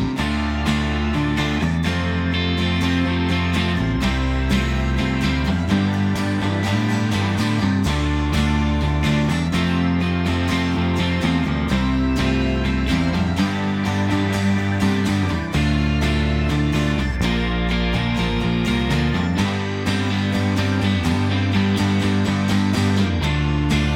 For Solo Male Duets 3:21 Buy £1.50